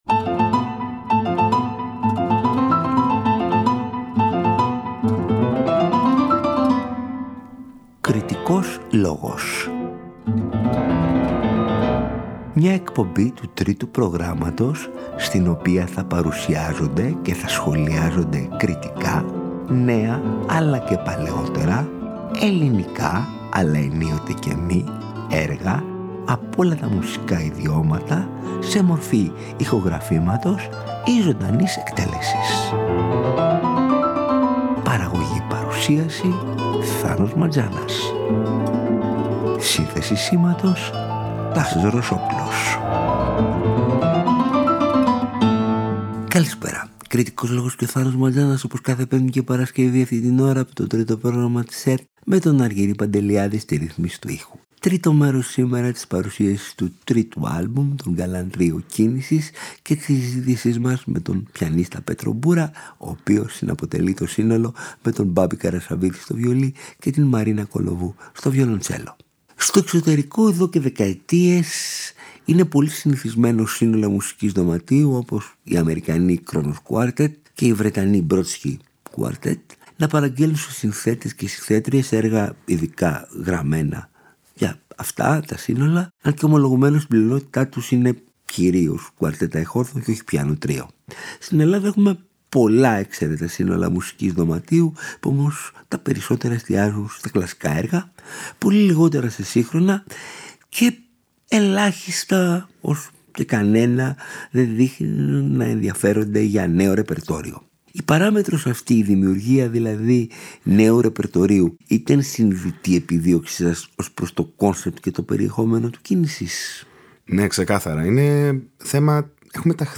Οι Galan Trio είναι ένα σύνολο μουσικής δωματίου, ένα πιάνο τρίο συγκεκριμένα το οποίο αποτελείται από τρείς εξαίρετους σολίστ
Διακρίνονται για την πολύ μεγάλη μεταξύ τους υφολογική και στιλιστική ευρύτητα, από πιάνο τρίο της σπουδαίας παράδοσης του ρομαντισμού και της προγραμματικής μουσικής μέχρι τις πιο σύγχρονες τάσεις και την ατονικότητα.